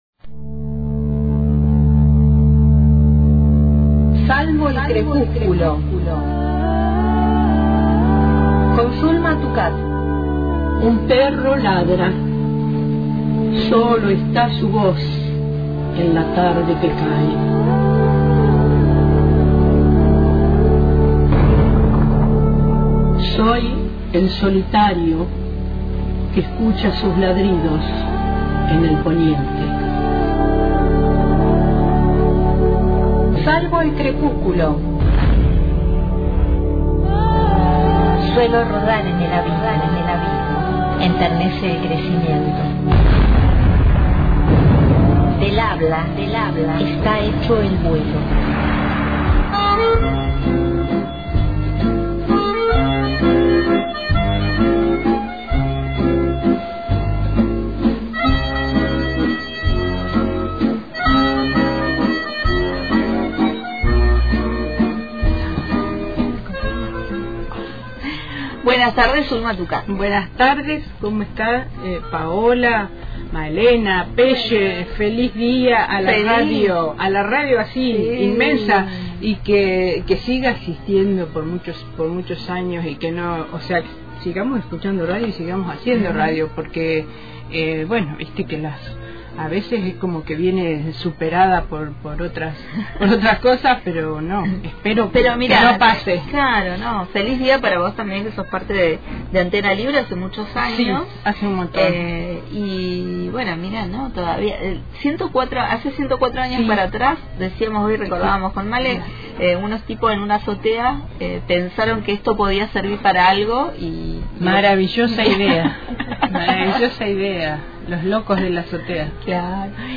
Al finalizar, nos leyó algunos de sus poemas.